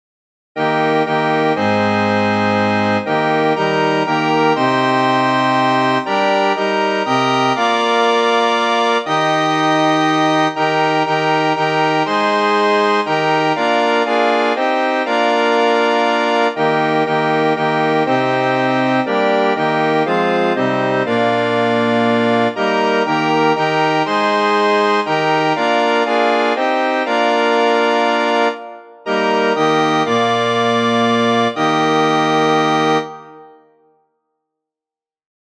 Flauta Historia del traductor: Juan B. Cabrera Letra: PowerPoint , PDF Música: PDF , MIDI , MXL ¡Aleluya!